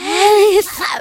• Samples de  Voz